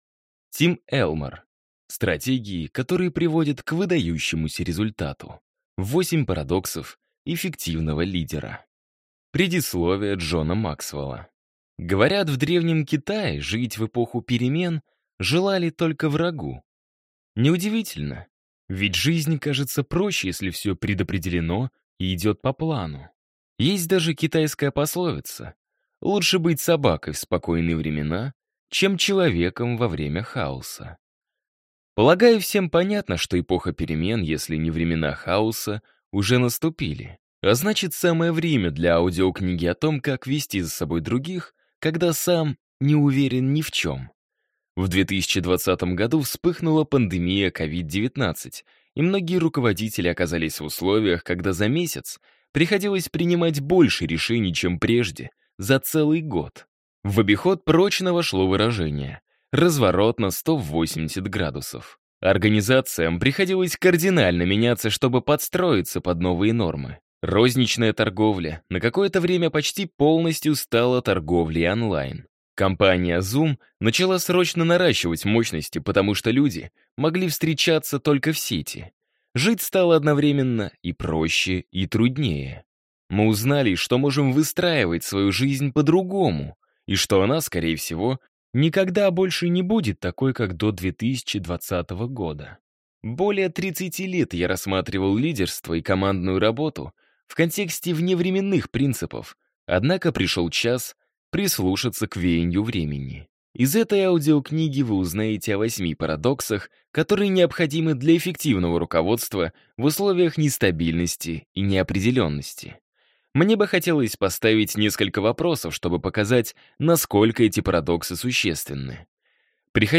Аудиокнига Стратегии, которые приводят к выдающемуся результату. 8 парадоксов эффективного лидера | Библиотека аудиокниг